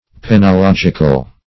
Penological \Pen`o*log"ic*al\, a. Of or pertaining to penology.